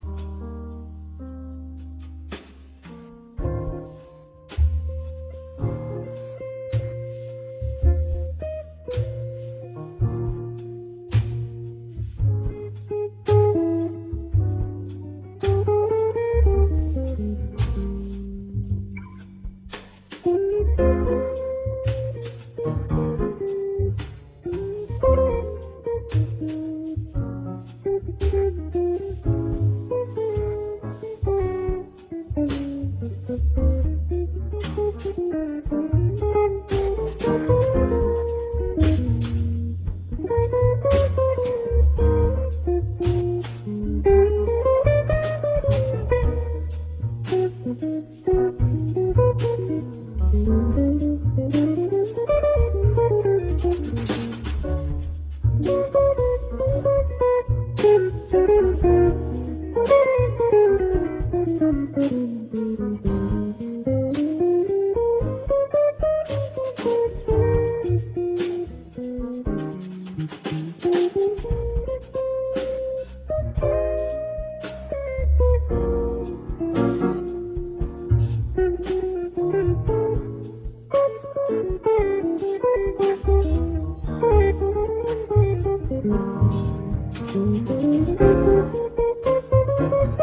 piano digitale
Guitar solo
Radio quality 1'30'' 230 K
My jazz 4tet